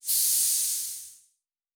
pgs/Assets/Audio/Sci-Fi Sounds/MISC/Air Hiss 1_02.wav at master
Air Hiss 1_02.wav